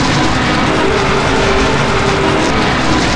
RUSTLOOP3.WAV